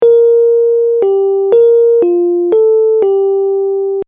↑マップセレクトで流れる